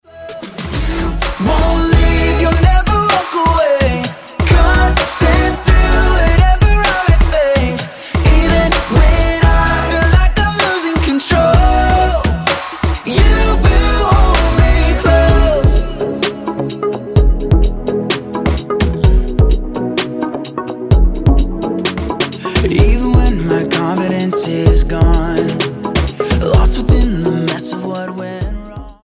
STYLE: Pop
hugely catchy pop clearly targeted at the young